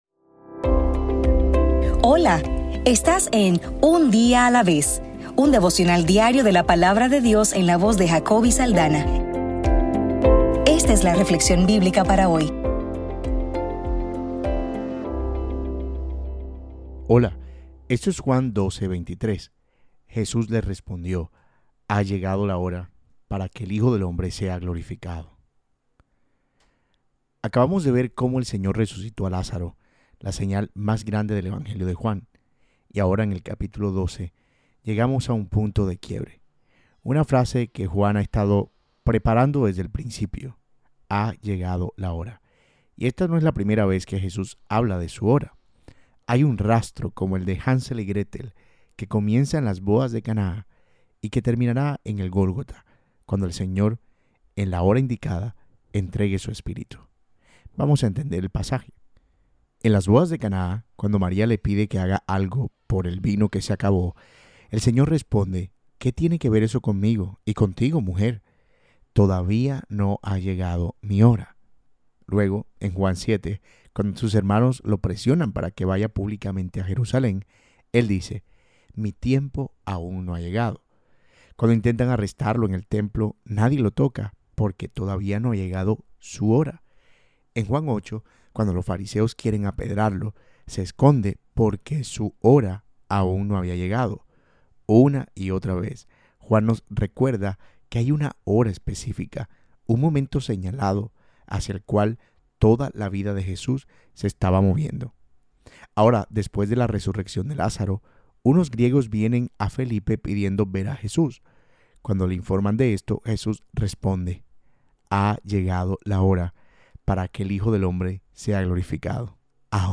Devocional para el 22 de diciembre